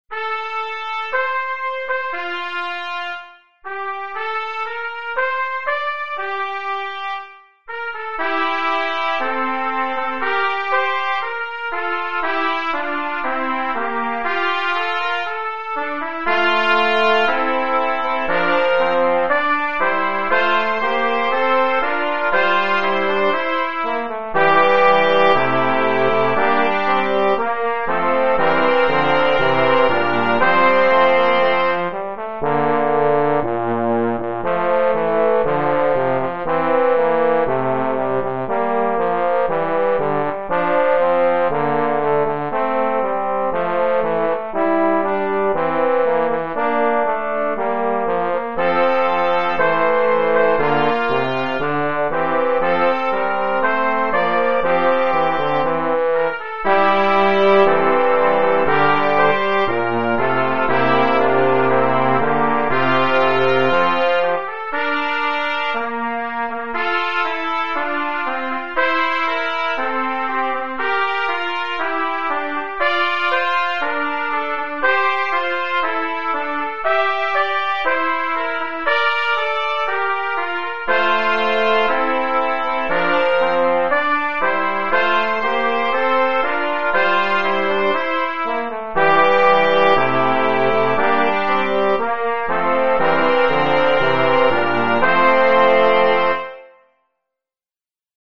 Fuque on themes from O Canada